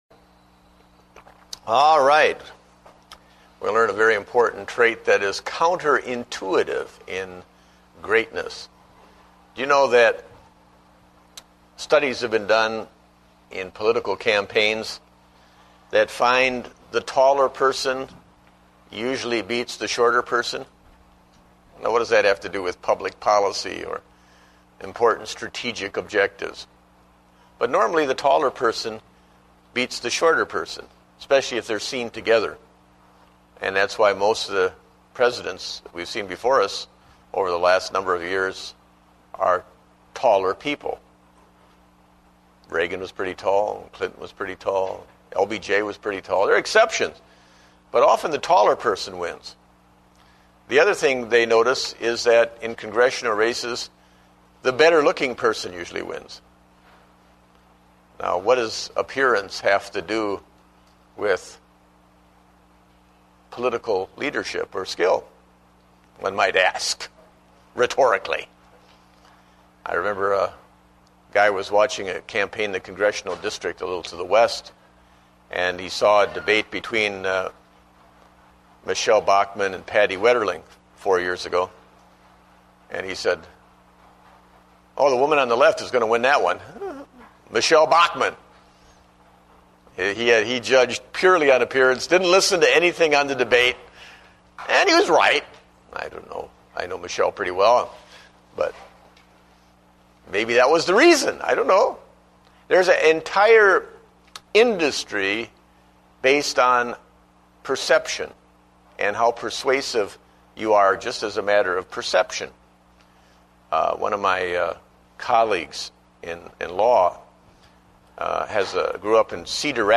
Date: August 9, 2009 (Adult Sunday School)